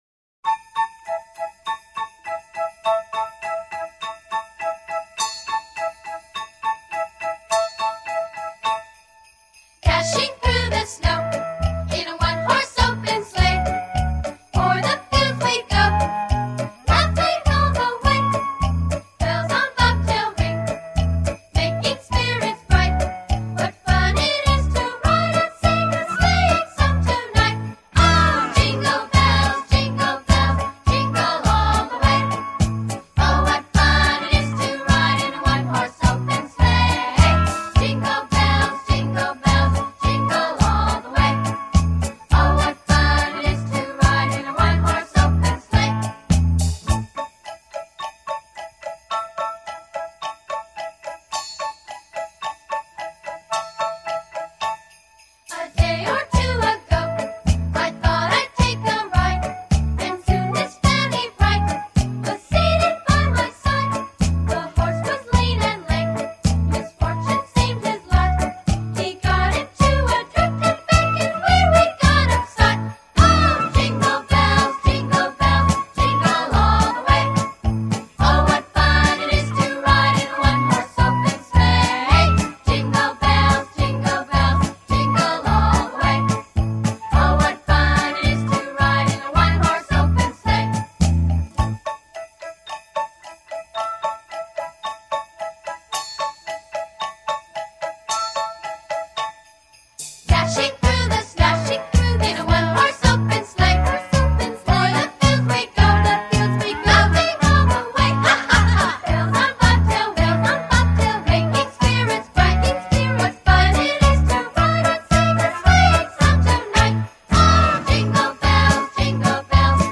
[21/12/2008][不一样的铃儿响叮当]童声合唱版